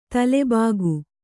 ♪ tale bāgu